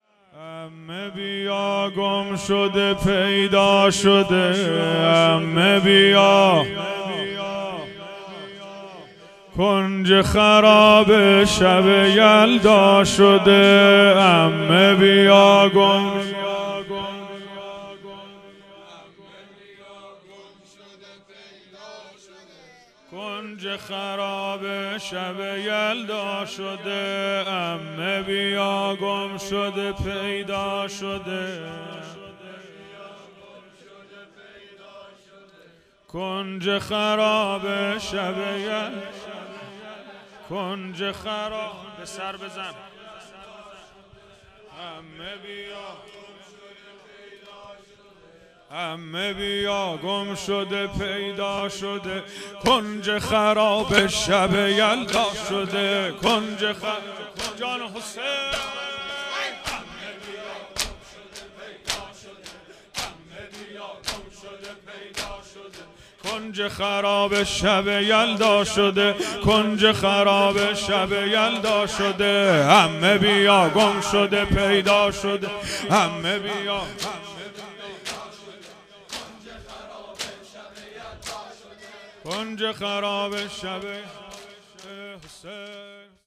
دودمه|عمه بیا گمشده پیدا شده
دهه اول محرم الحرام ۱۴۴۴